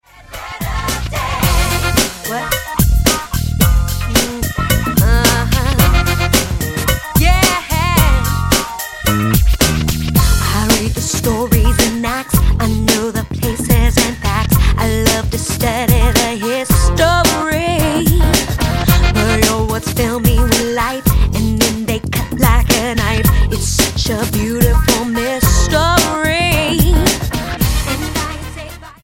STYLE: Pop